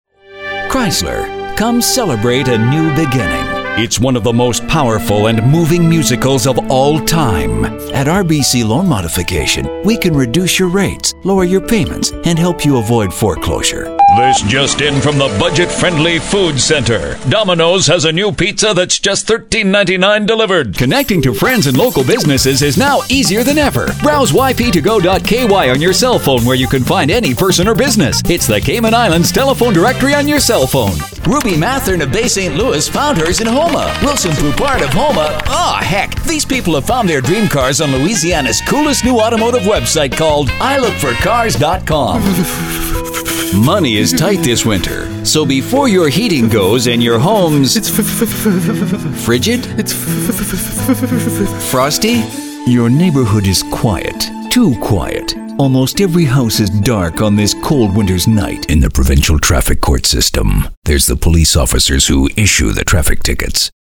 Voice over talent with audio production studio specializing in tv and radio commercial production, multi-media narration and character voices
Sprechprobe: Werbung (Muttersprache):